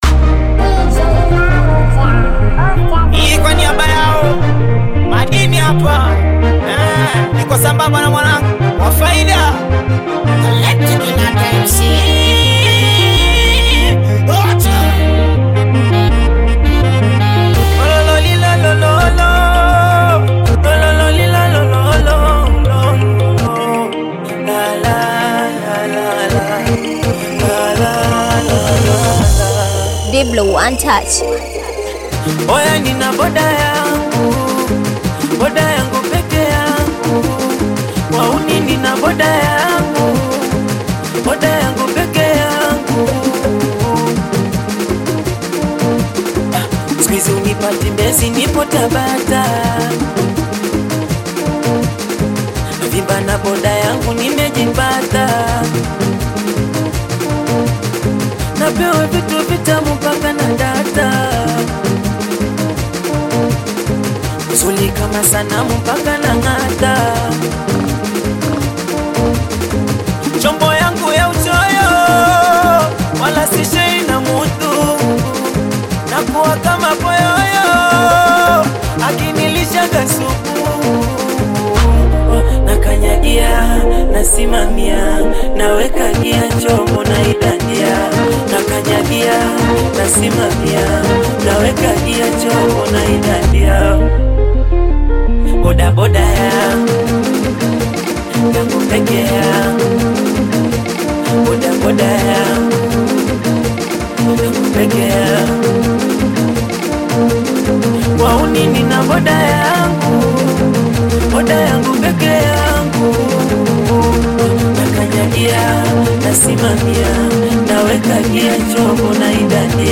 Tanzanian Bongo Flava
energetic Singeli song